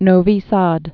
(nōvē säd)